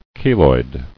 [ke·loid]